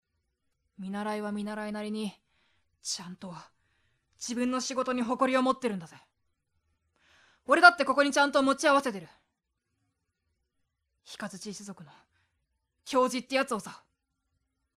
【サンプルセリフ】
（見習いを戦わせるわけにはいかないと言う年嵩の仲間に向かって、真剣に）